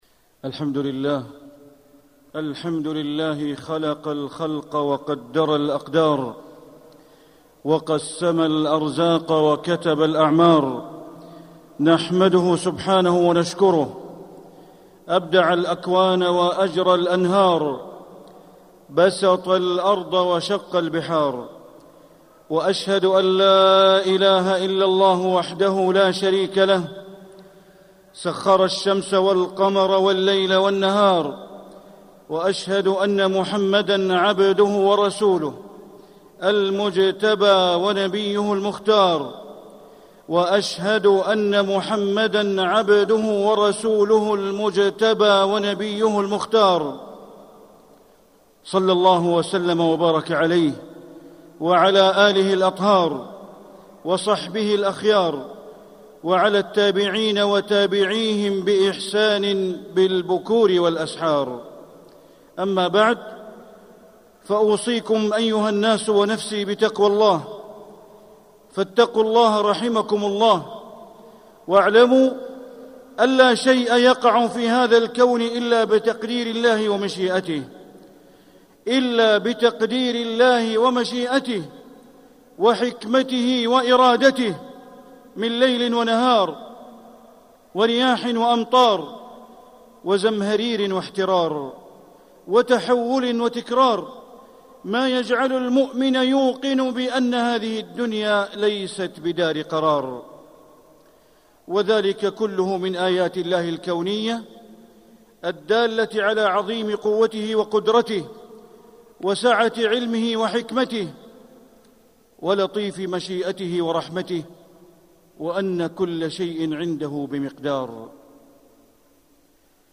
مكة: موعظة الحر - بندر بن عبد العزيز بليلة (صوت - جودة عالية. التصنيف: خطب الجمعة